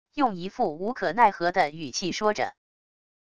用一副无可奈何的语气说着wav音频